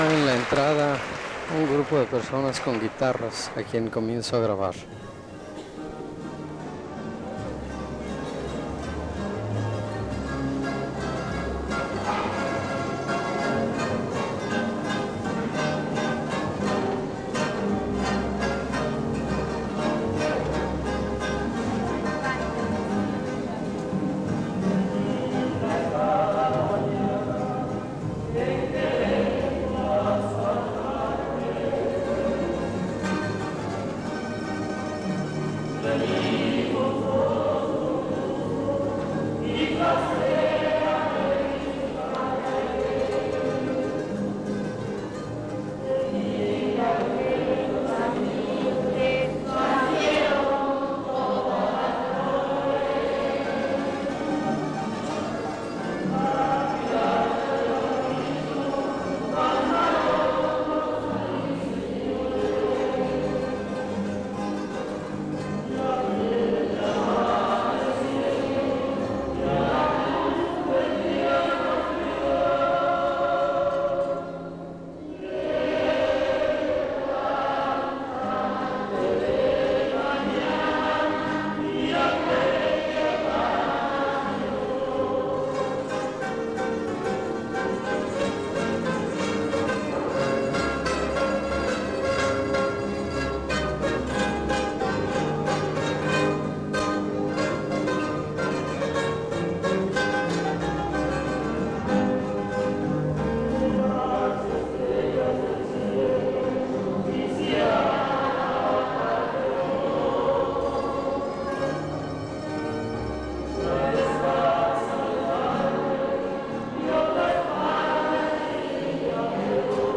Fiesta del Señor Santiago